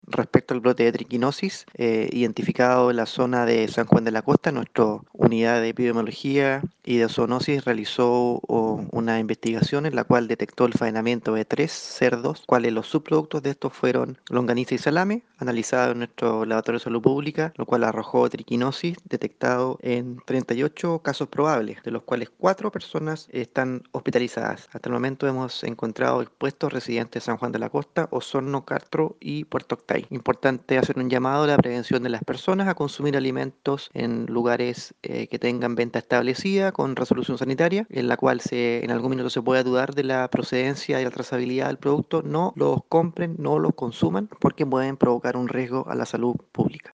Respecto a este brote en la región se refirió el jefe provincial de la Seremi de Salud de Osorno, Felipe Vergara: